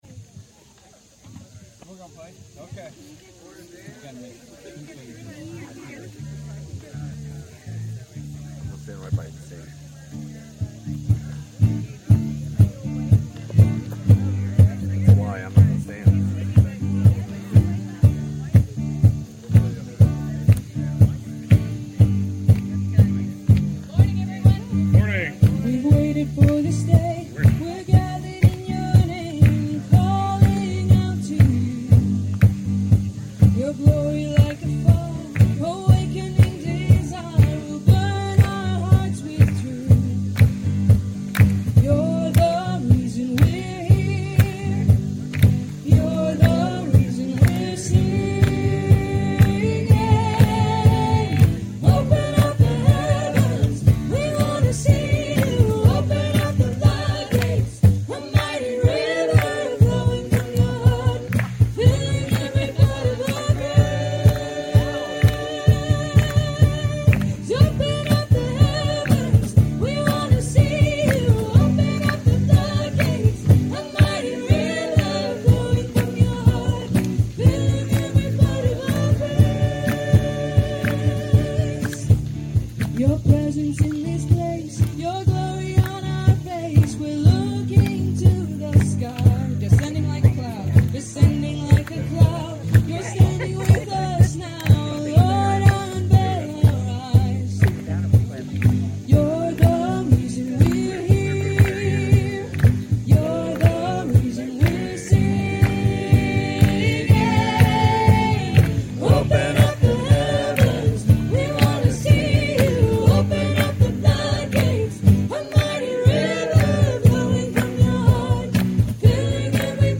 This week, we are coming to you from the GFC Annual Family Campout!